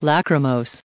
added pronounciation and merriam webster audio
982_lachrymose.ogg